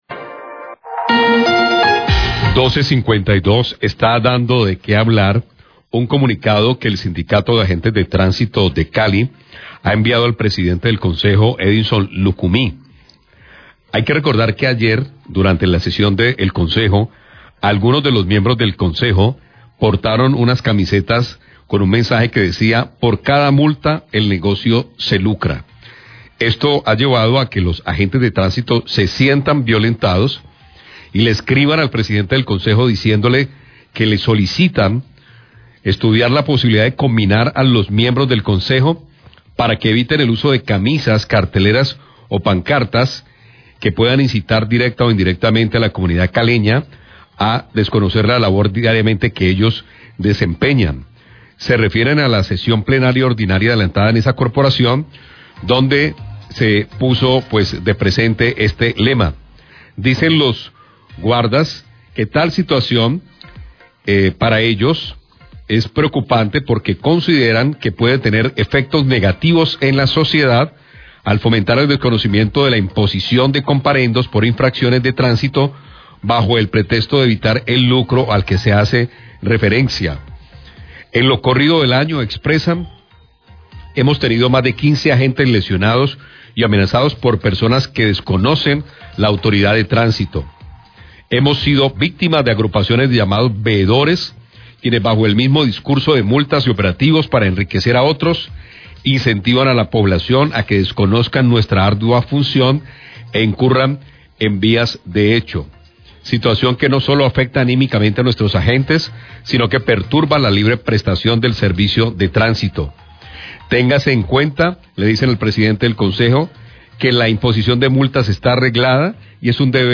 NOTICIERO RELÁMPAGO